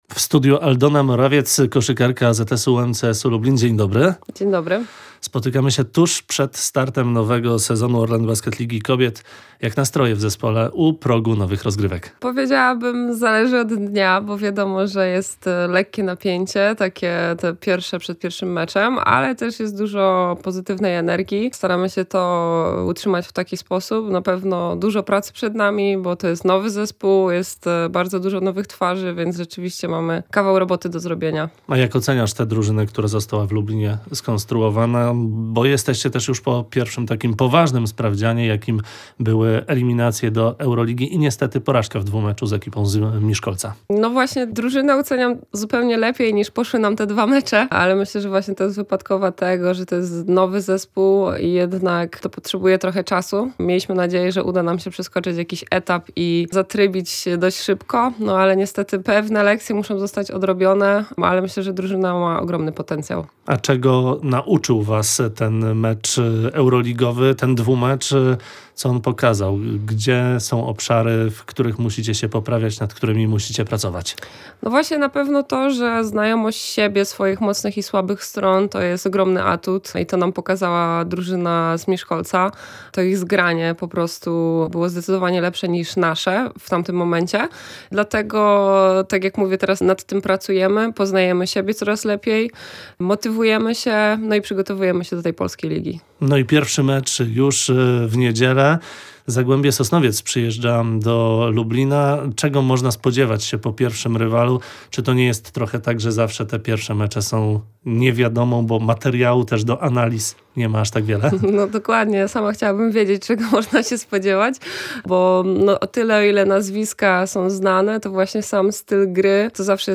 Lubelskie koszykarki przed startem sezonu. Rozmowa